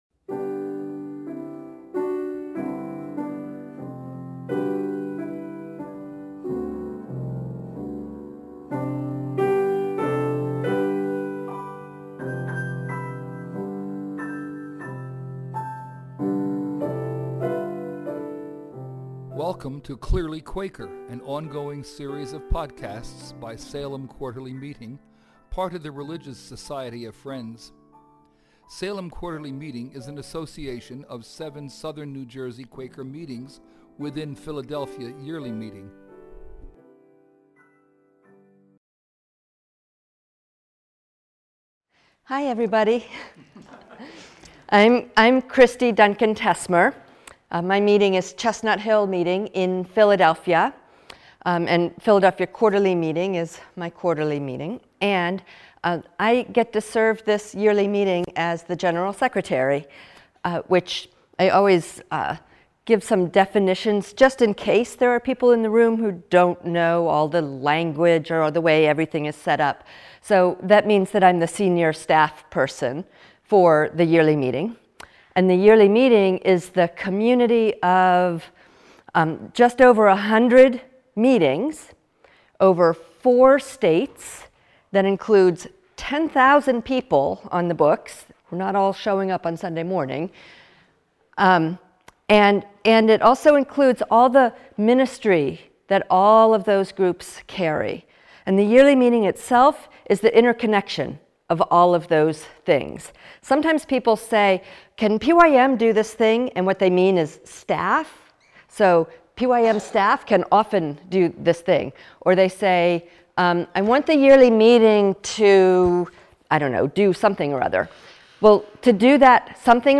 Held at the Salem Friends Meetinghouse.